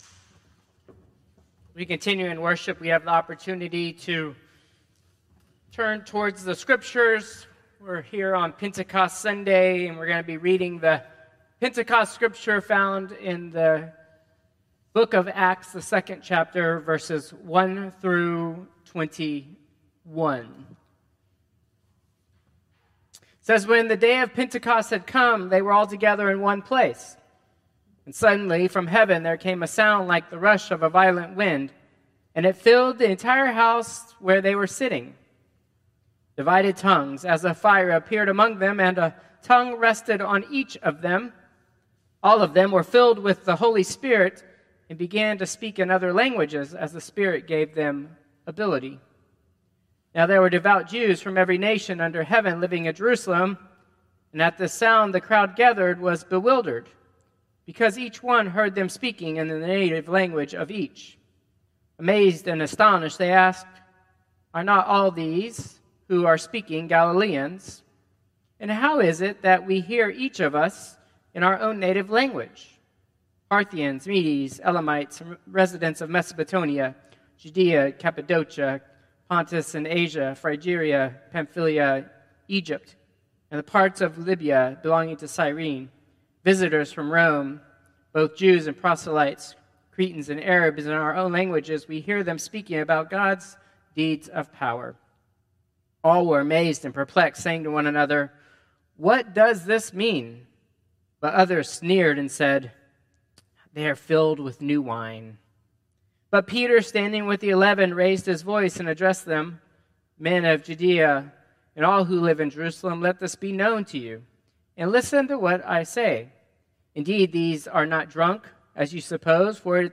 Traditional Service 6/8/2025